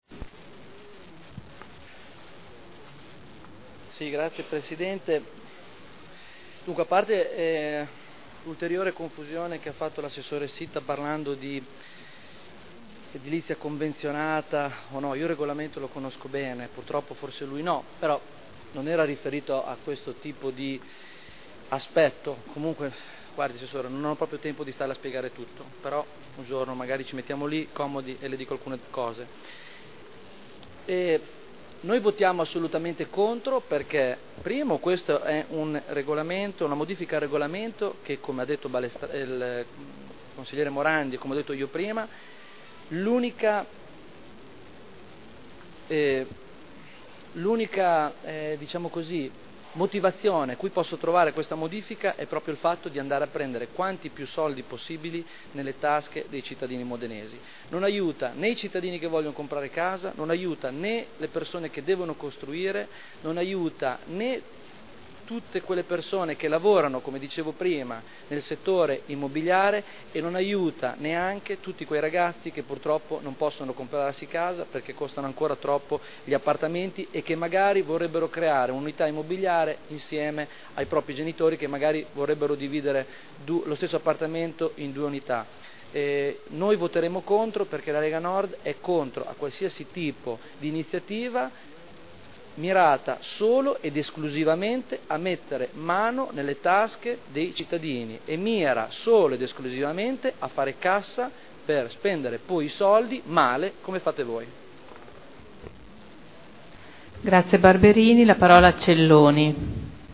Stefano Barberini — Sito Audio Consiglio Comunale
Seduta del 6/12/2010. Dichiarazione di voto su delibera: Art. 14.1 del testo coordinato delle norme di PSC (Piano Strutturale Comunale) POC (Piano Operativo Comunale) RUE (Regolamento Urbanistico Edilizio) – Modifiche al regolamento attuativo – Approvazione (Commissione consiliare del 30 novembre 2010)